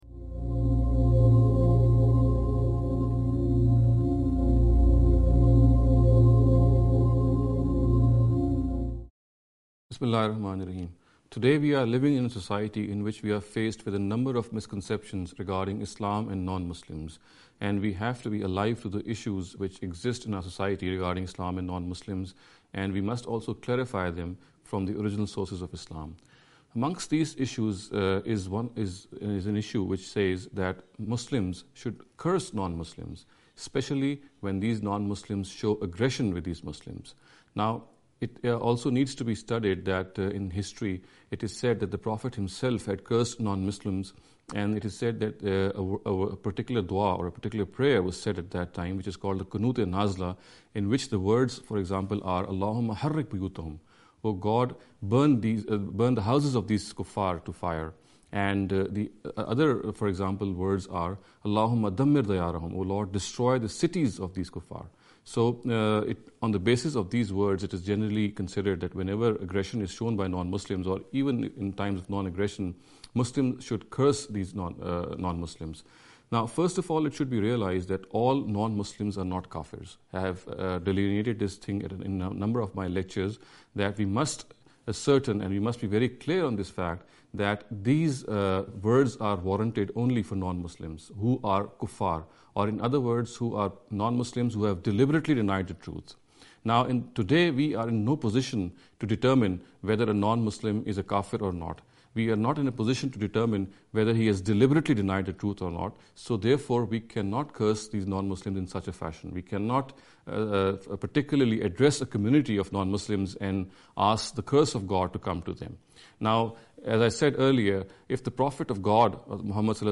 This lecture series will deal with some misconception regarding the Islam and Non-Muslims.